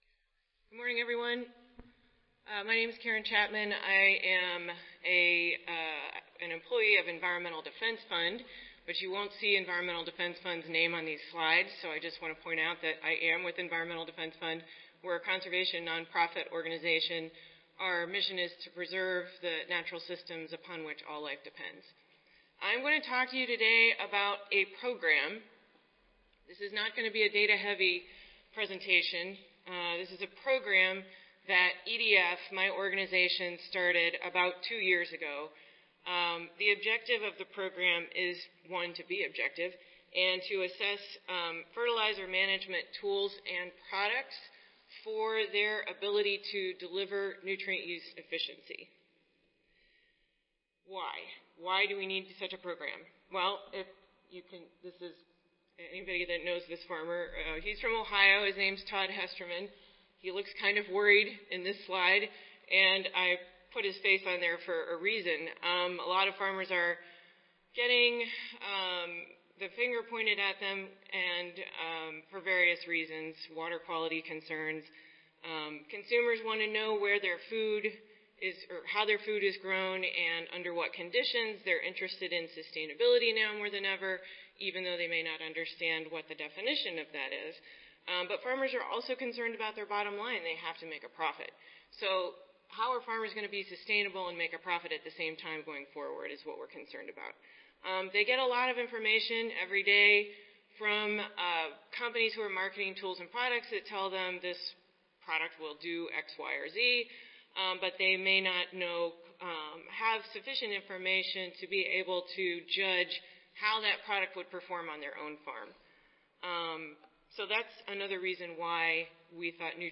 S Squared Audio File Recorded Presentation